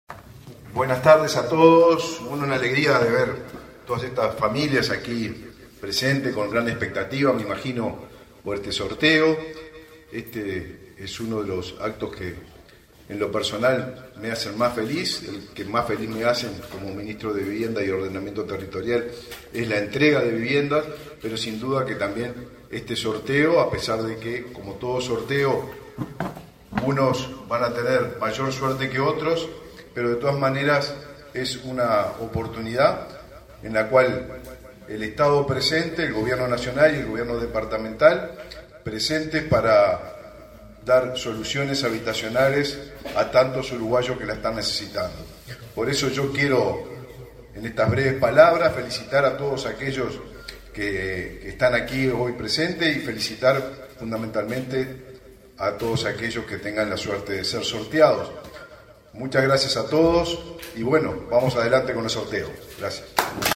Palabras del ministro del Vivienda, Raúl Lozano
Palabras del ministro del Vivienda, Raúl Lozano 01/09/2023 Compartir Facebook X Copiar enlace WhatsApp LinkedIn El Ministerio de Vivienda y Ordenamiento Territorial (MVOT) realizó, este 1.° de setiembre, el sorteo de 27 viviendas de un edificio en la ciudad de Tacuarembó. En el acto participó el ministro Raúl Lozano.